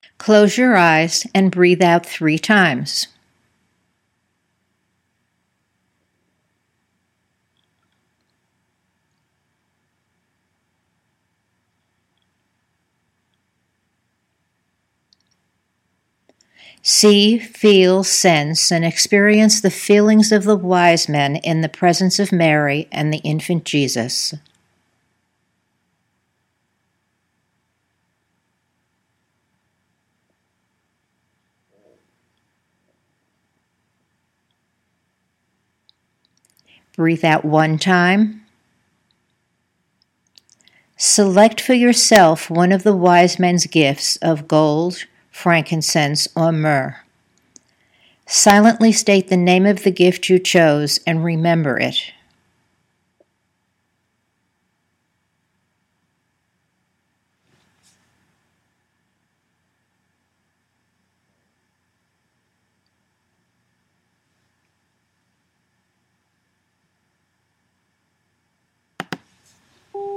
When an Imagery instruction is complete, there are 15 seconds of silence on the tape for your Imagery to emerge.  When that time is over, you’ll hear a tone that is signaling you to breathe out one time and open your eyes.